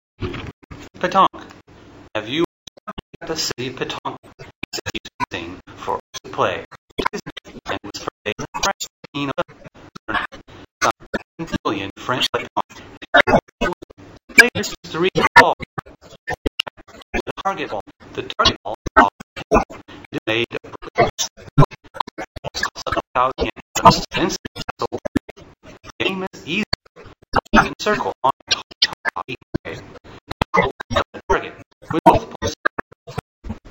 42 Petanque ＲＥＡＤＩＮＧ
(fast)